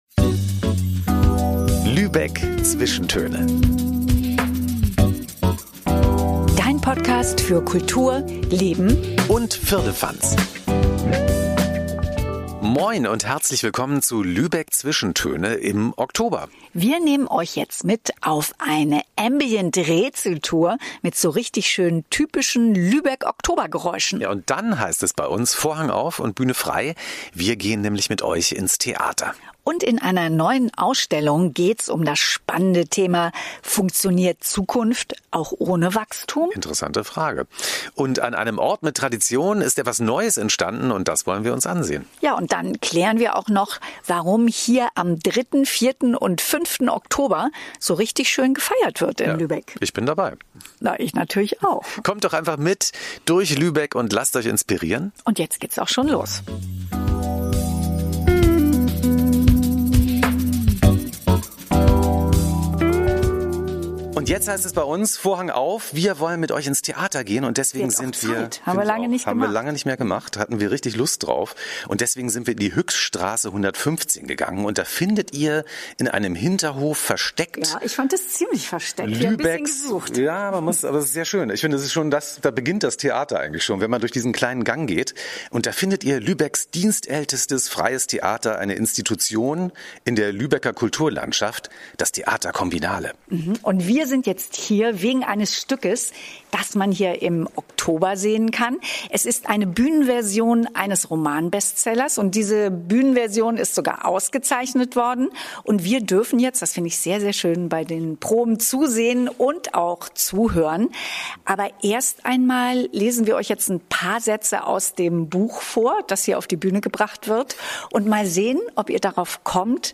Und wir nehmen dich diesmal mit auf eine Ambient-Rätsel-Tour, mit typischen Lübeck-Oktober-Geräuschen.